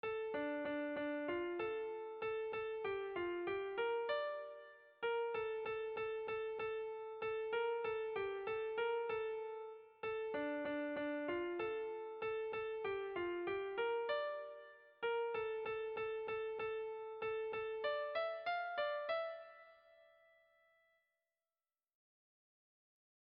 Bertso melodies - View details   To know more about this section
Dantzakoa
ABAB2